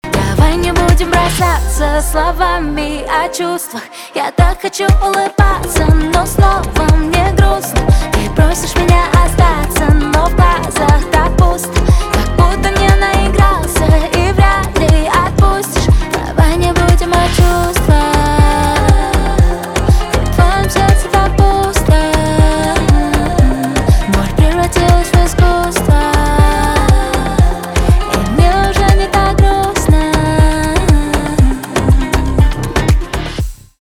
поп
грустные
гитара